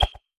throw.ogg